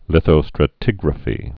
(lĭthō-strə-tĭgrə-fē)